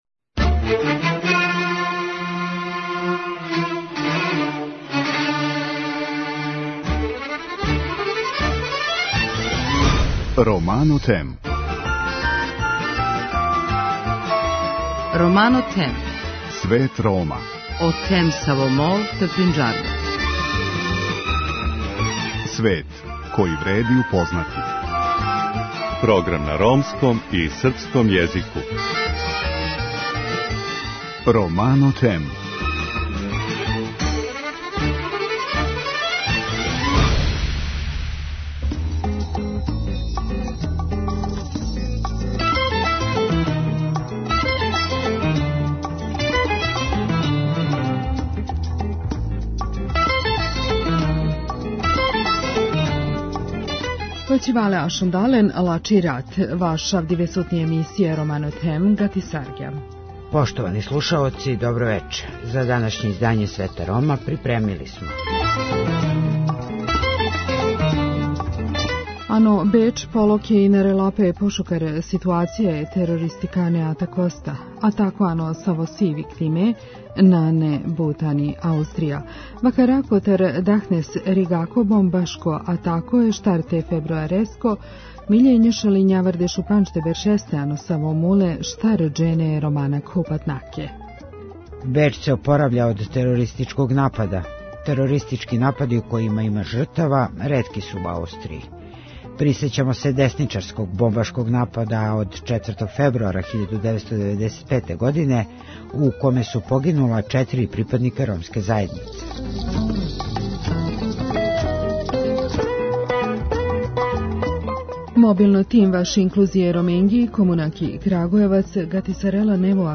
Вести на ромском језику